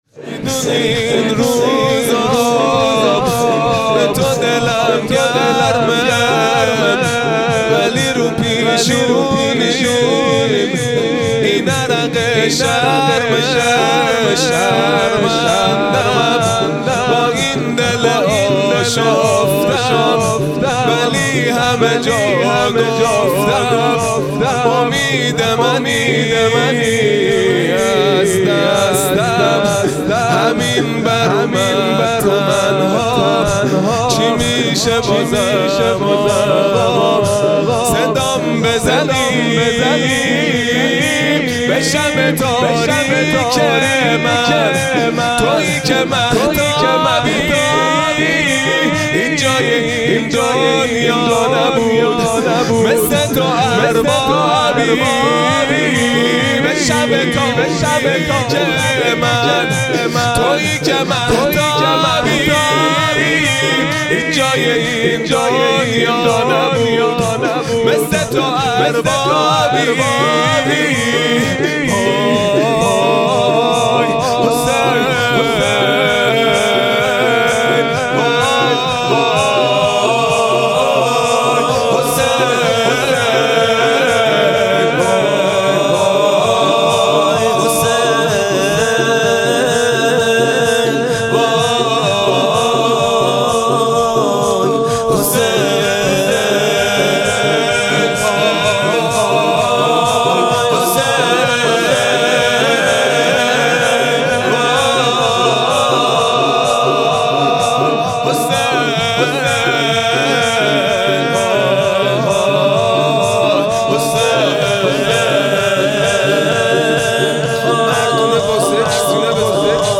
شور | میدونی این روزا، به تو دلم گرمه | پنج شنبه 9 بهمن 1399
جلسۀ هفتگی | وفات حضرت حضرت ام البنین(سلام الله علیها) | پنج شنبه 9 بهمن 1399